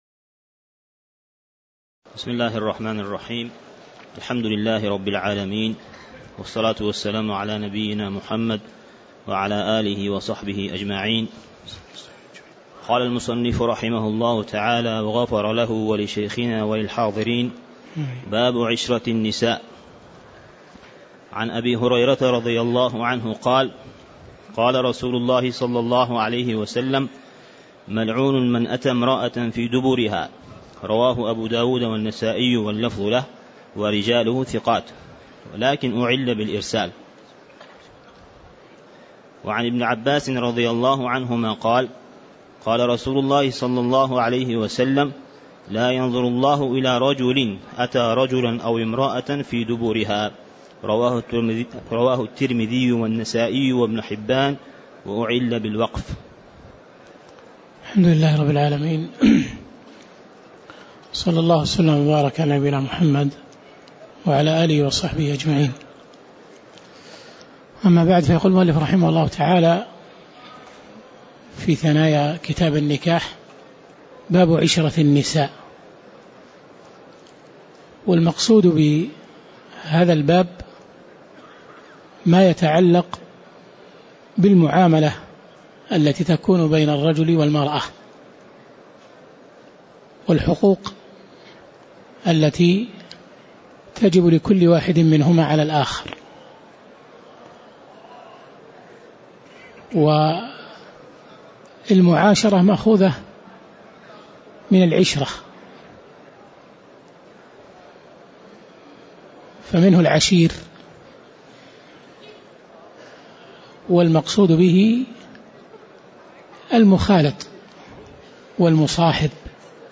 تاريخ النشر ١٤ جمادى الأولى ١٤٣٧ هـ المكان: المسجد النبوي الشيخ